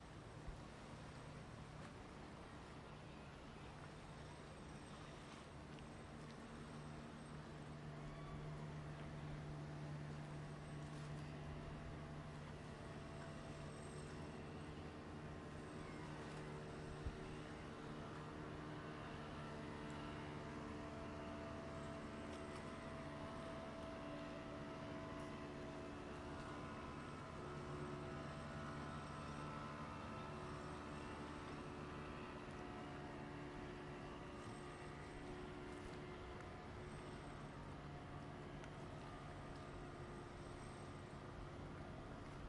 描述：About 80 sea containers moving downstream on a ship towards Rotterdam (nl). I'm sitting on the riverbank with a recording Edirol R09.
标签： engine fieldrecording nature noise river traffic water
声道立体声